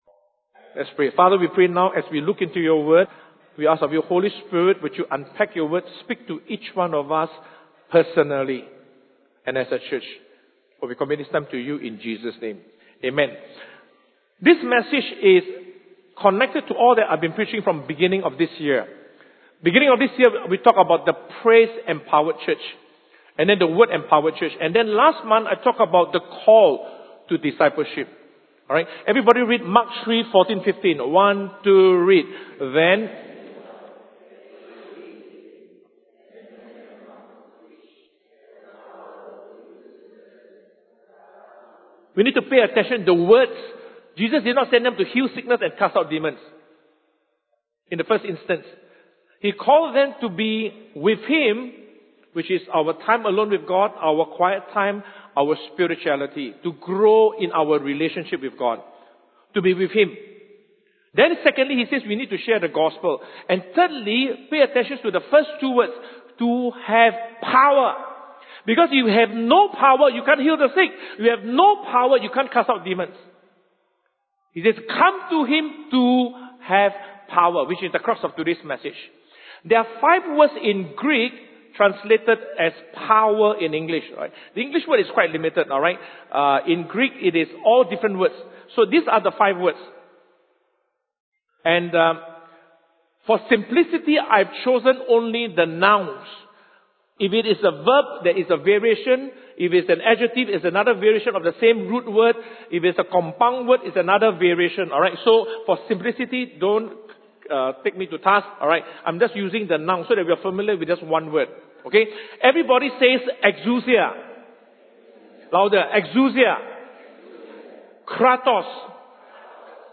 In the first sermon of a two-part series, SP unpacked the meaning and application of power and its role in our lives as believers and disciples of Christ.